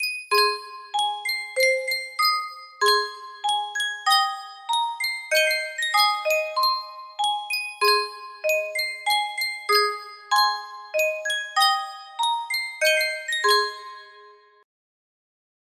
Yunsheng Spieluhr - Der Lindenbaum 5871 music box melody
Full range 60